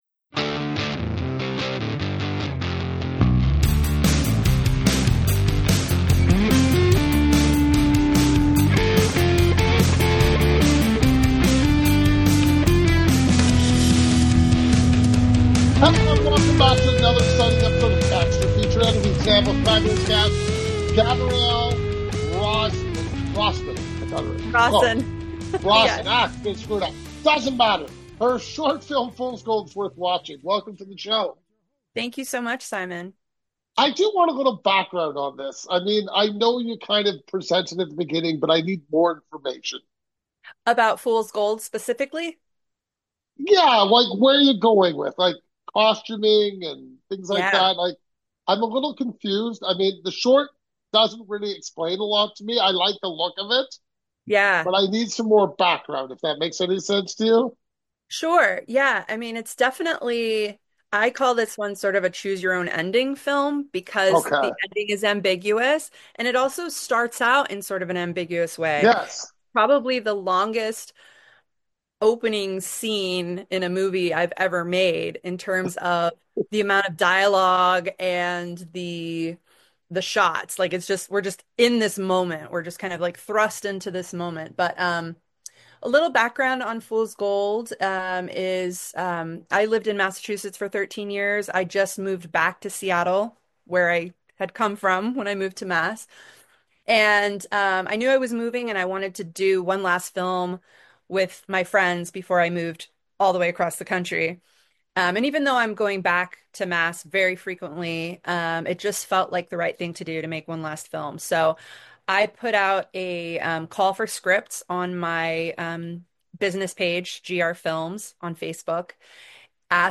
Extra Features Interviews